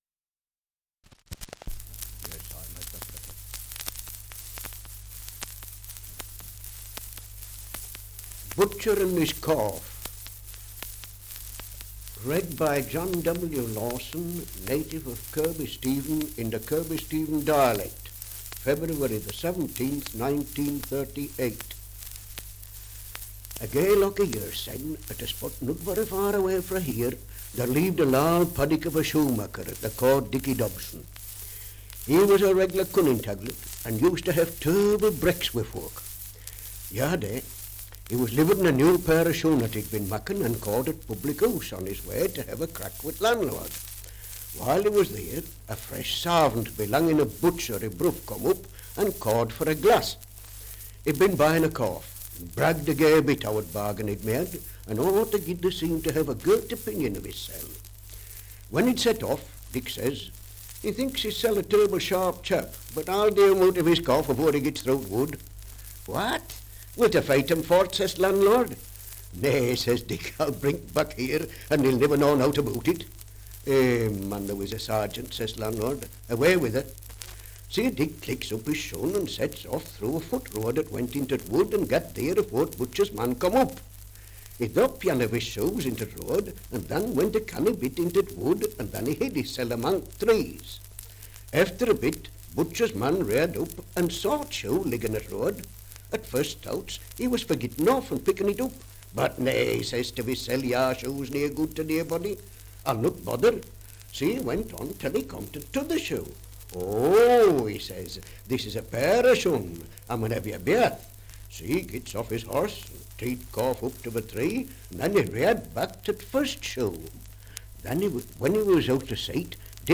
Dialect recording in Kirkby Stephen, Westmorland
78 r.p.m., cellulose nitrate on aluminium
English Language - Dialects
Oral Traditions